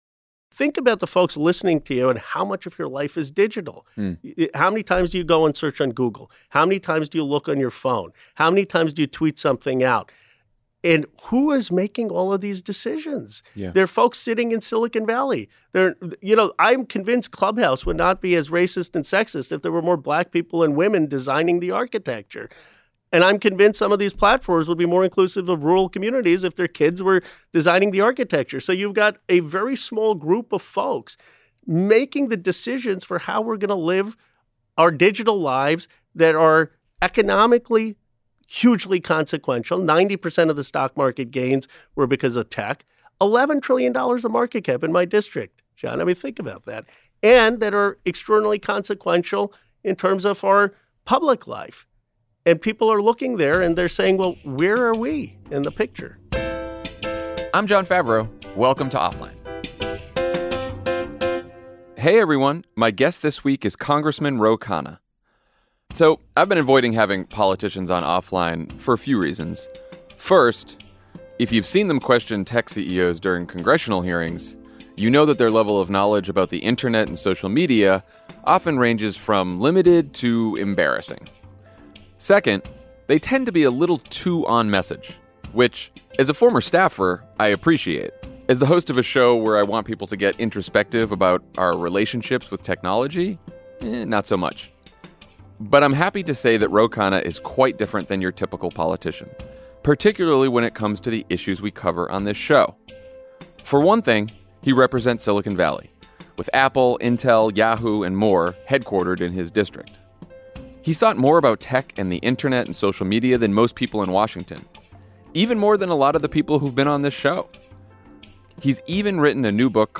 Congressman Ro Khanna, Silicon Valley’s most progressive representative, joins Jon to talk about where tech went wrong and how we can get it back on course. Providing legislative solutions, he teaches Jon how we can use technology to create economic opportunity and a functioning, healthy, multiracial democracy.